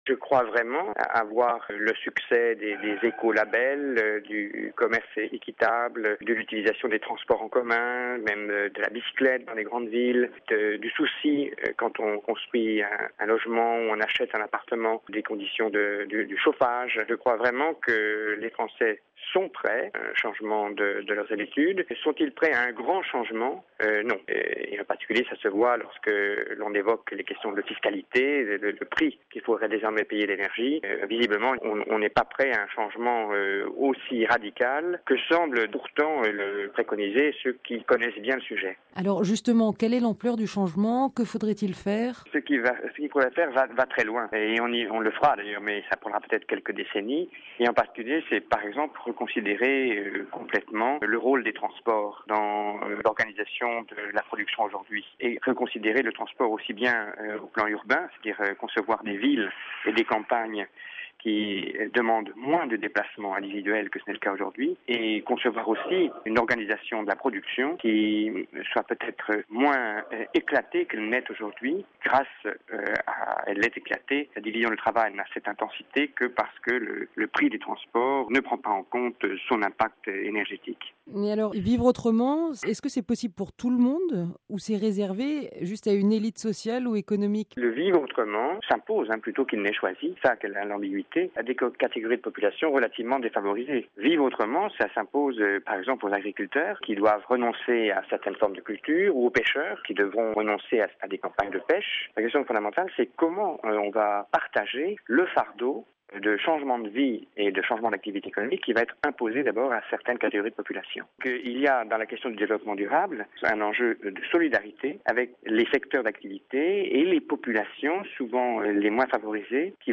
était interrogé par